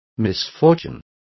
Also find out how desdicha is pronounced correctly.